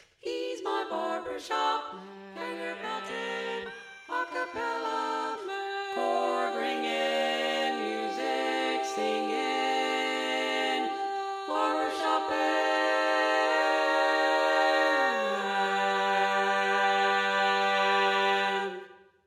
Key written in: G Major
Type: Female Barbershop (incl. SAI, HI, etc)